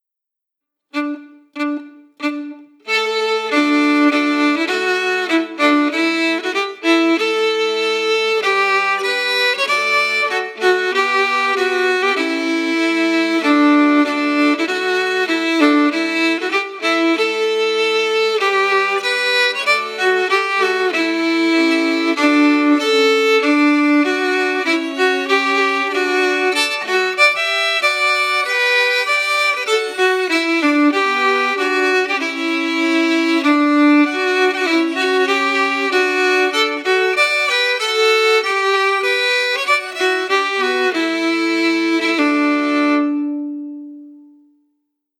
Key: D*
Form: March (marching song)
Melody emphasis
Genre/Style: Scottish marching song